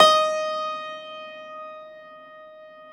53a-pno15-D3.wav